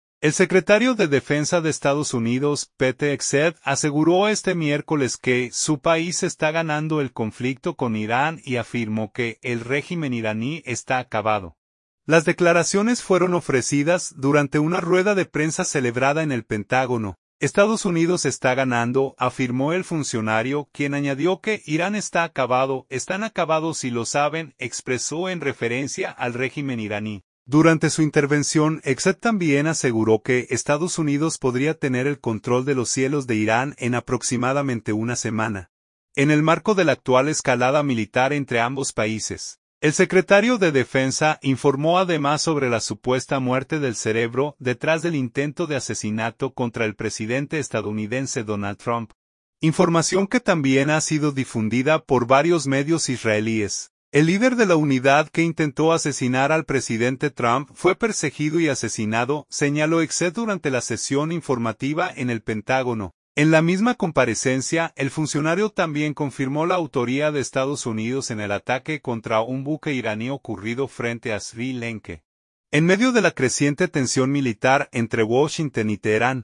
El secretario de Defensa de Estados Unidos, Pete Hegseth, aseguró este miércoles que su país está “ganando” el conflicto con Irán y afirmó que el régimen iraní “está acabado”, las declaraciones fueron ofrecidas durante una rueda de prensa celebrada en el Pentágono.
“El líder de la unidad que intentó asesinar al presidente Trump fue perseguido y asesinado”, señaló Hegseth durante la sesión informativa en el Pentágono.